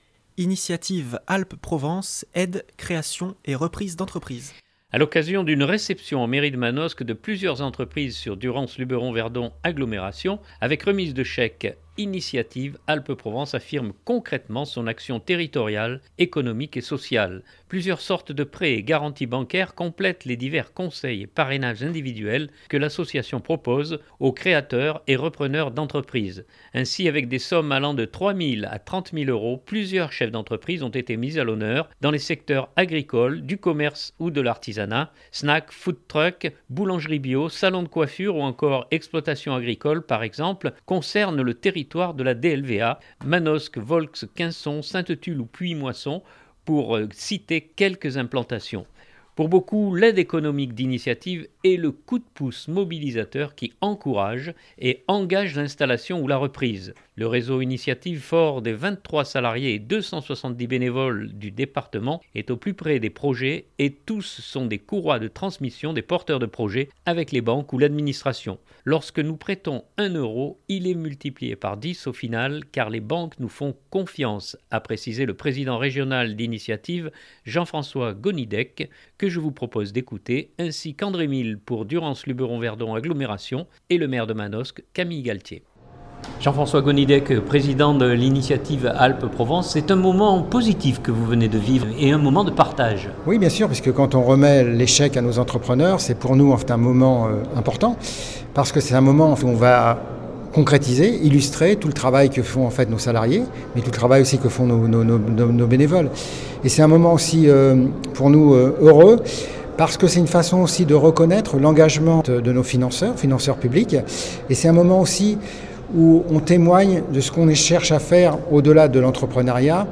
Initiative Alpes Provence aide création et reprise d’entreprise.mp3 (10.06 Mo) A l’occasion d’une réception en mairie de Manosque de plusieurs entreprises sur Durance-Luberon-Verdon Agglomération, avec remise de chèques, Initiative Alpes Provence affirme concrètement son action territoriale, économique et sociale. Plusieurs sortes de prêts et garanties bancaires complètent les divers conseils et parrainages individuels que l’association propose aux créateurs et repreneurs d’entreprises. Ainsi avec des sommes allant de 3 000 à 30 000 € plusieurs chefs d’entreprise ont été mis à l’honneur dans les secteurs agricole, du commerce ou de l’artisanat.